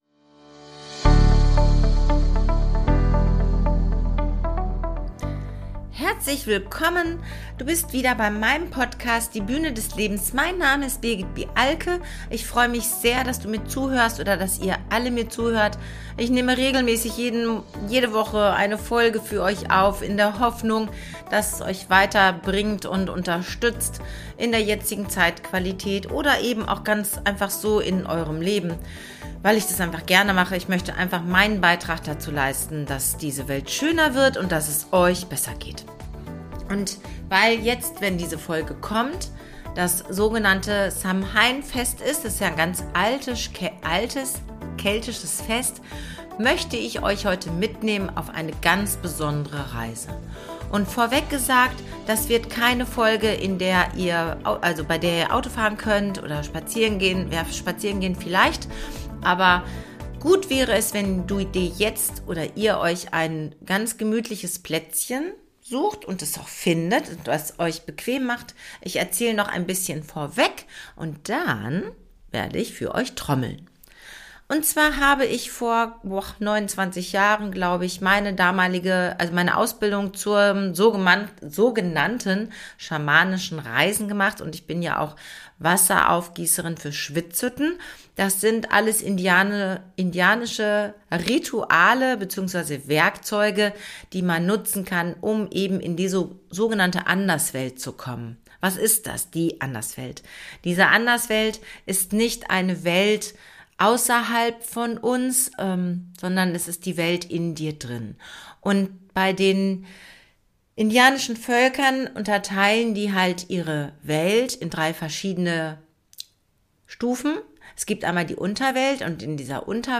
Durch das monotone schlagen der Trommel werden eure Gehirnhälften gleich geschaltet, so dass du die Möglichkeit eine Reise in dein Unterbewusstsein machen kannst.